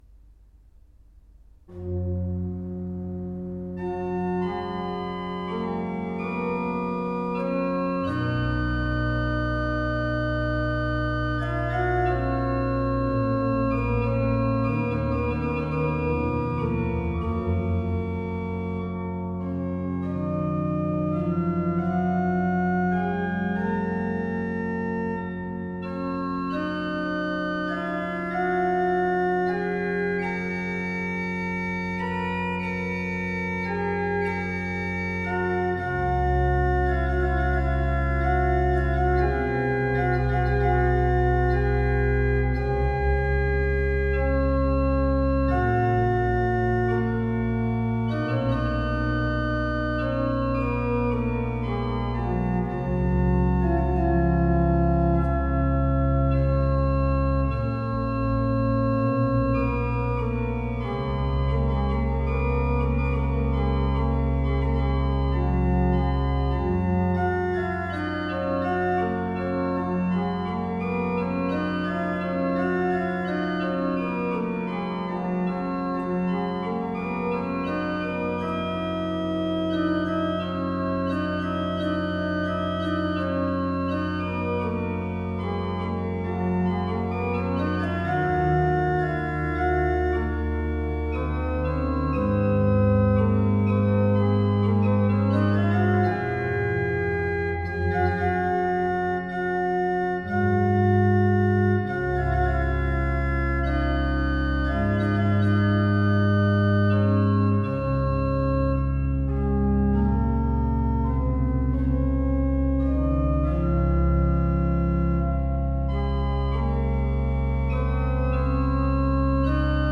Banque de son de l'orgue Isnard de la basilique de Saint-Maximin-la-Sainte-Baume
À l'orgue Hauptwerk Mixtuur-II, Le Vauroux, enregistrement le 27 octobre 2023